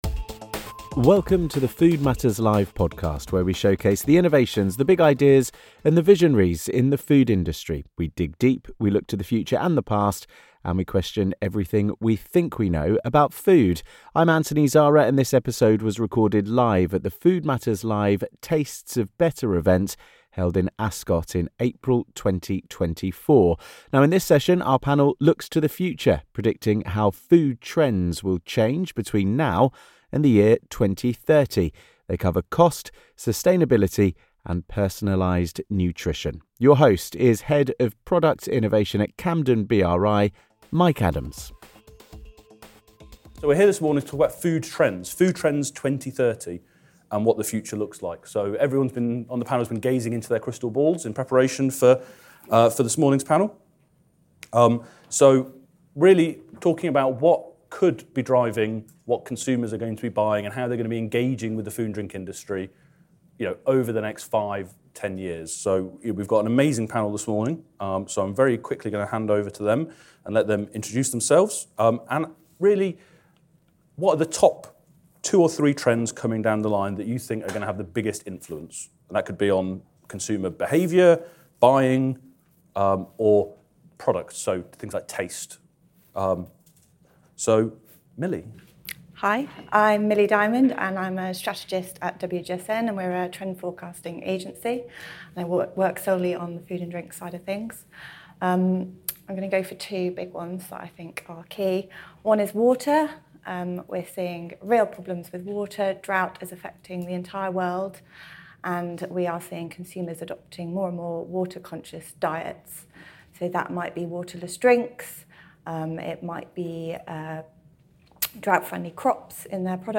In this episode of the Food Matters Live podcast, recorded live at the Food Matters Live Tastes of Better event held in Ascot in April 2024, we ask an expert panel for their insights and try to answer some of those questions. We cover the cost-of-living, sustainability, personalised nutrition, and plenty more.